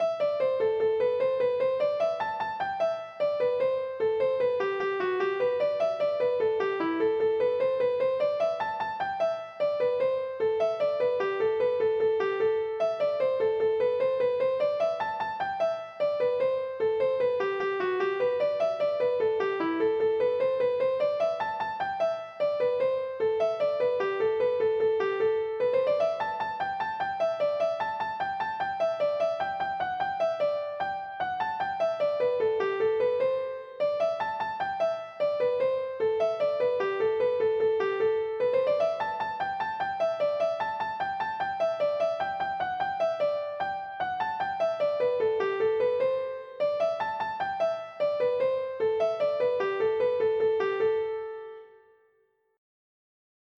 hornpipe